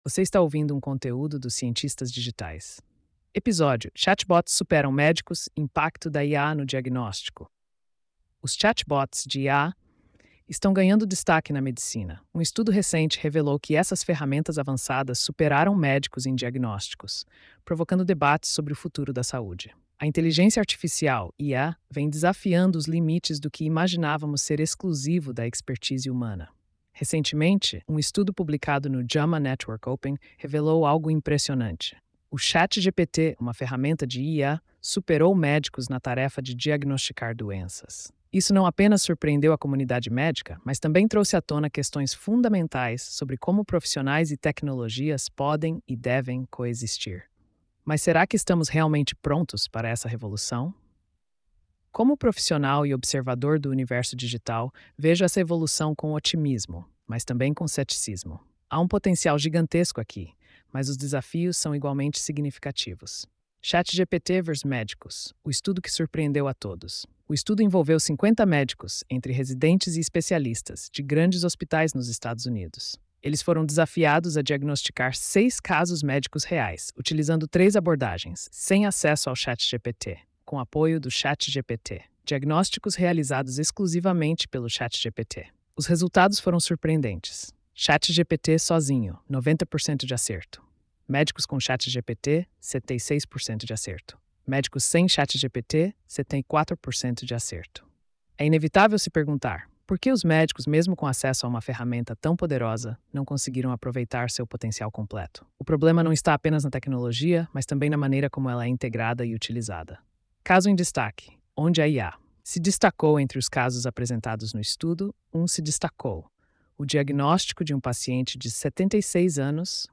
post-2585-tts.mp3